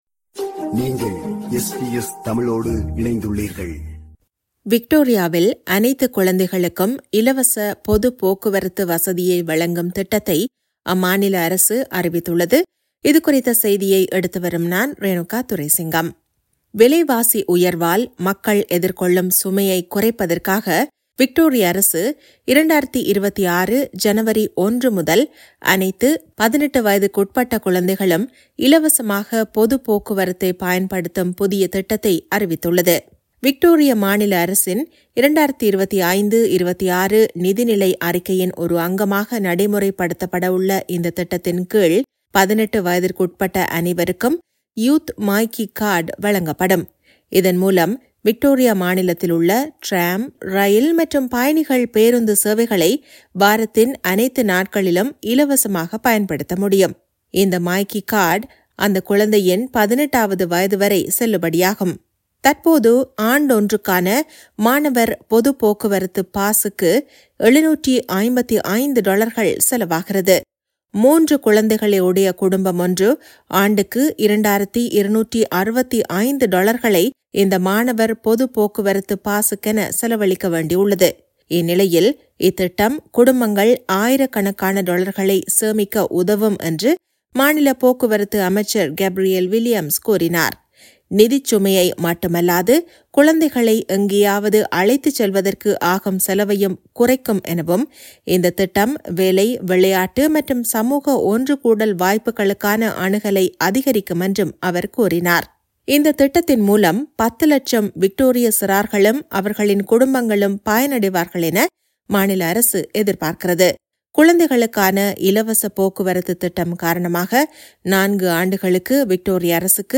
விக்டோரியாவில் அனைத்து குழந்தைகளுக்கும் இலவச பொதுப் போக்குவரத்து வசதியை வழங்கும் திட்டத்தை அம்மாநில அரசு அறிவித்துள்ளது. இதுகுறித்த செய்தியை எடுத்துவருகிறார்